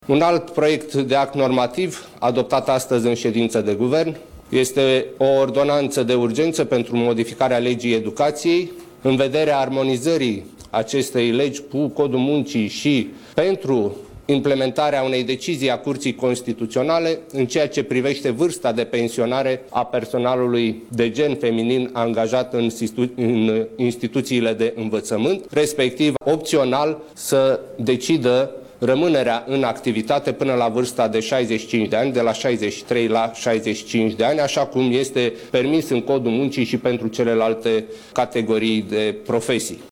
Șeful Cancelariei Guvernului Ionel Dancă: